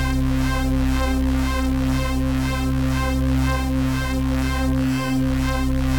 Index of /musicradar/dystopian-drone-samples/Tempo Loops/120bpm
DD_TempoDroneD_120-C.wav